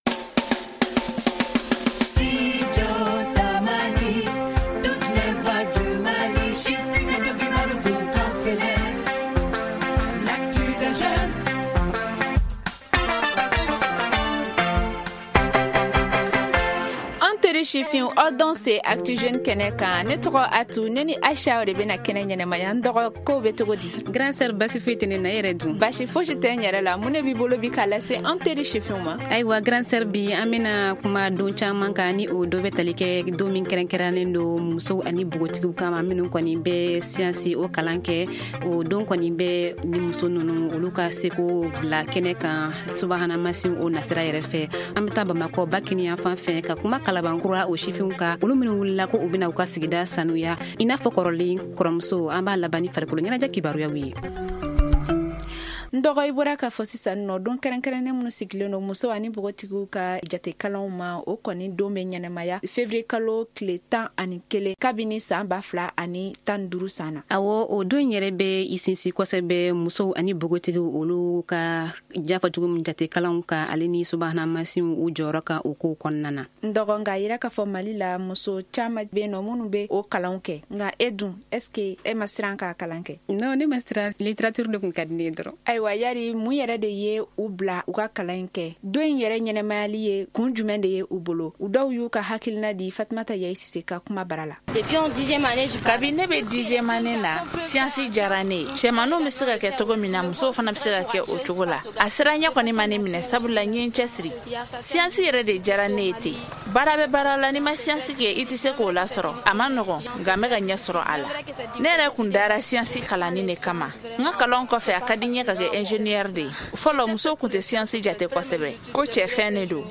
Journal en français: Télécharger
Au Mali, quelques filles sinteressent à ce domaine, mais le nombre reste insuffisant, selon des universitaires. Suivez un micro trottoir avec des filles dans cette édition.